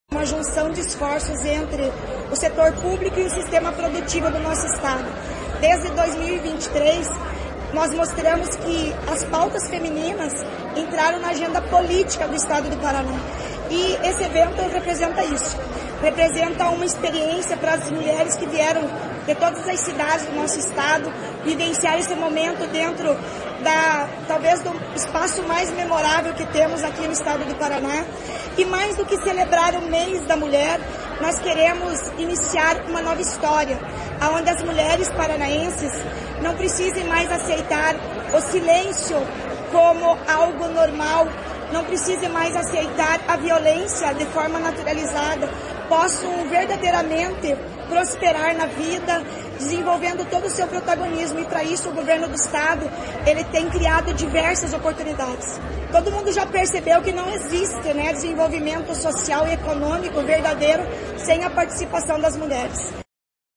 Sonora do governador Ratinho Júnior sobre o protagonismo feminino